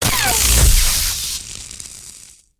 shielddeflect.ogg